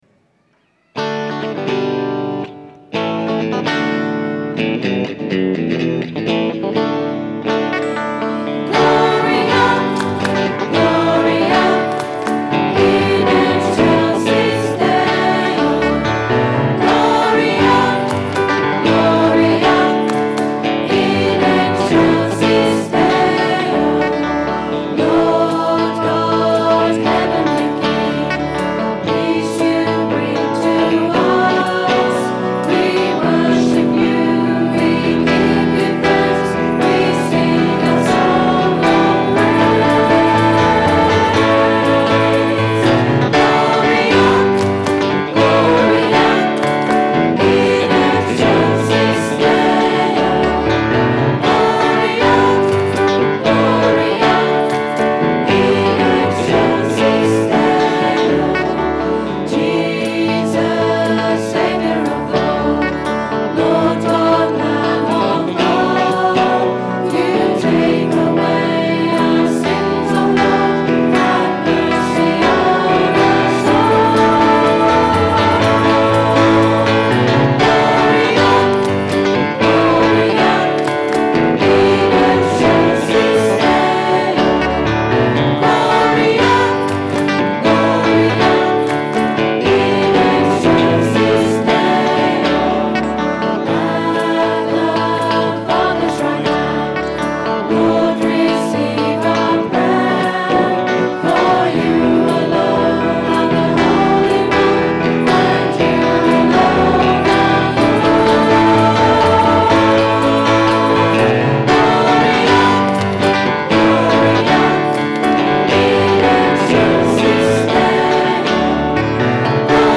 Recorded on a Zoom H4 digital stereo recorder at 10am Mass Sunday 4th July 2010.